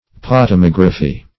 Search Result for " potamography" : The Collaborative International Dictionary of English v.0.48: Potamography \Pot`a*mog"ra*phy\, n. [Gr.
potamography.mp3